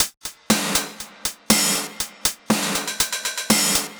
Index of /musicradar/retro-house-samples/Drum Loops
Beat 01 No Kick (120BPM).wav